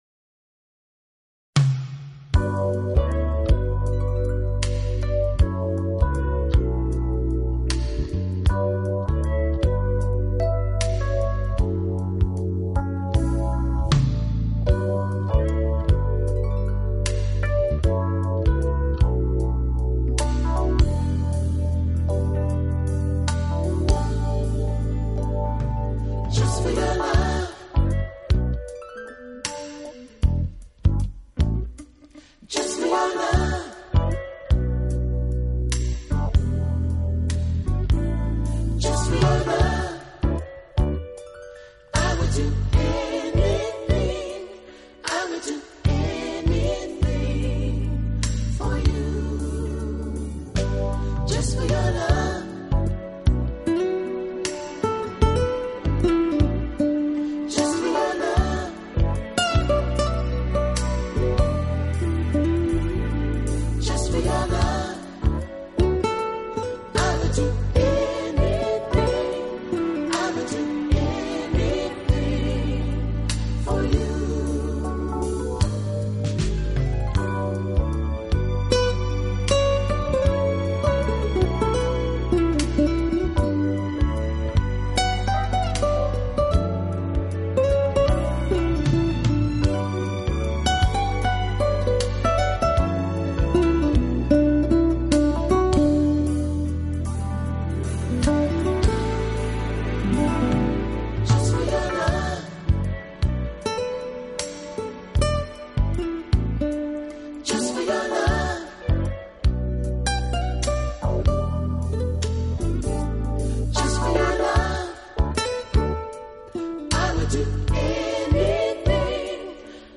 【爵士吉他】